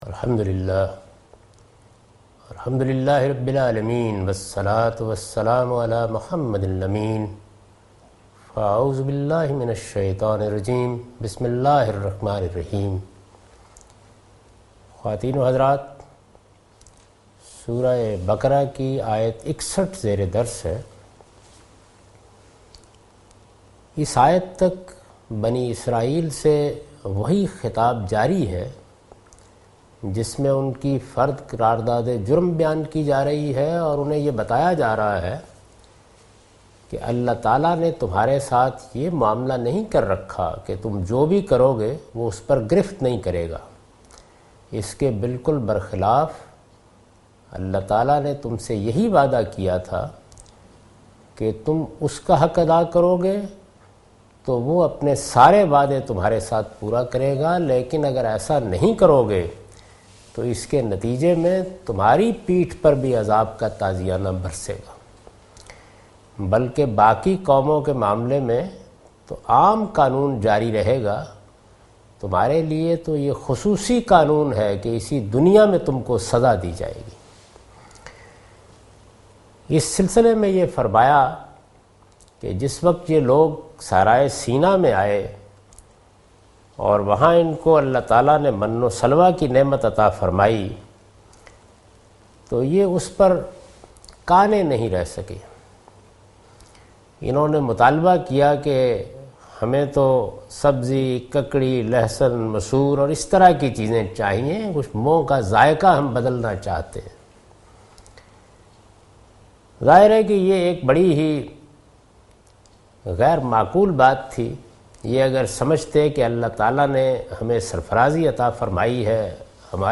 Surah Al-Baqarah - A lecture of Tafseer-ul-Quran – Al-Bayan by Javed Ahmad Ghamidi. Commentary and explanation of verse 61-62 (Lecture recorded on 12th Sep 2013).